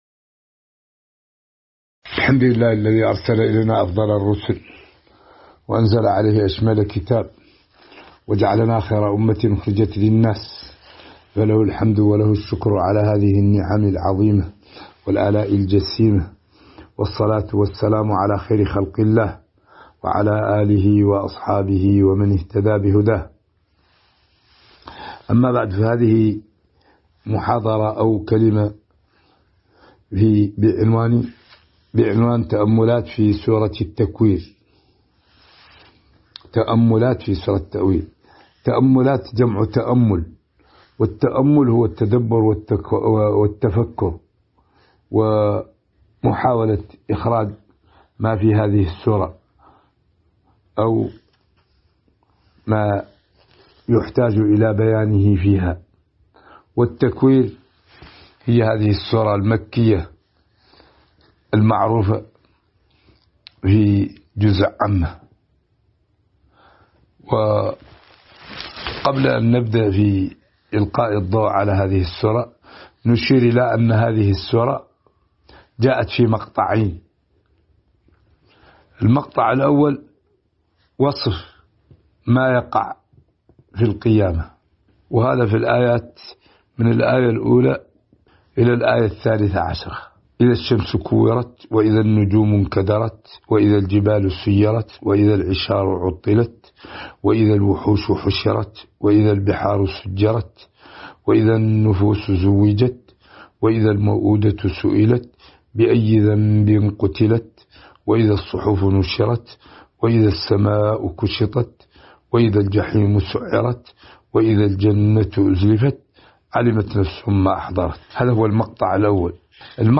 تاريخ النشر ١٤ شعبان ١٤٤٣ هـ المكان: المسجد النبوي الشيخ